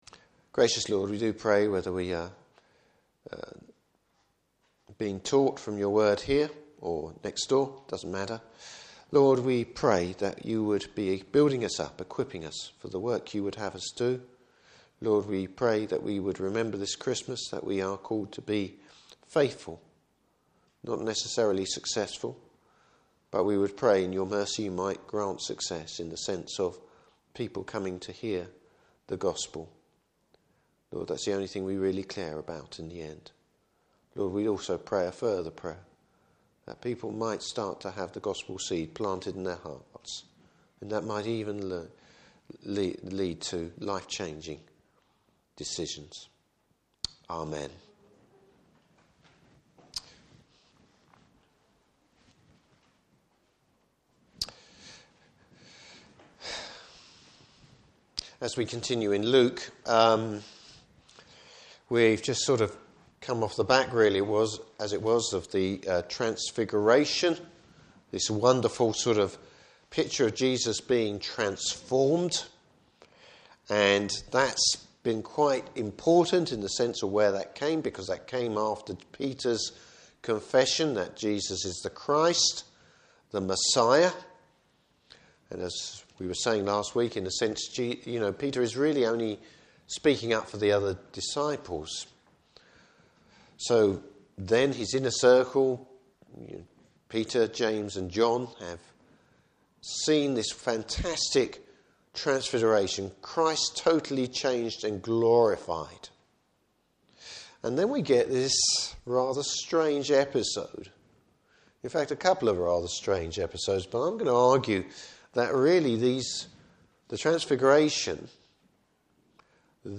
Service Type: Morning Service Bible Text: Luke 9:37-50.